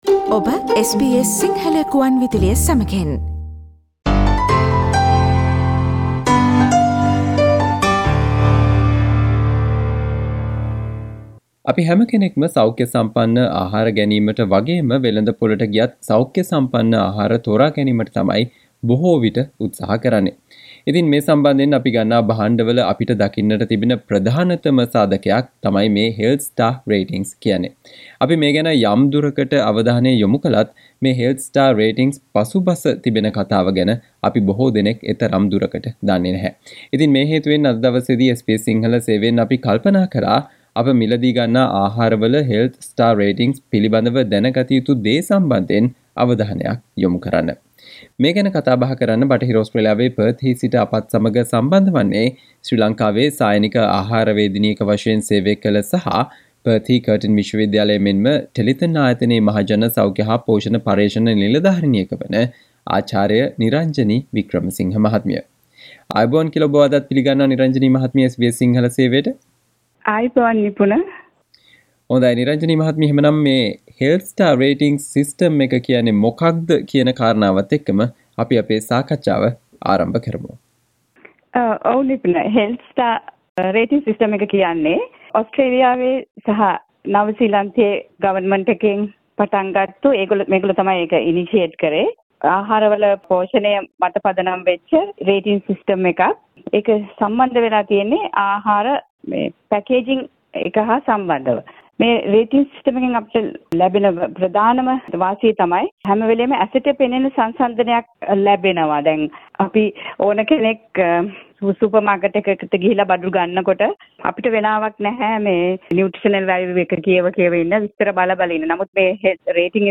අප ගන්නා අහාර වල Health Star Rating සැකසෙන්නේ කුමන පදනමක් මතද සහ එමගින් ඇති වාසි සම්බන්ධයෙන් SBS සිංහල සේවය සිදු කල සාකච්චාවට සවන්දෙන්න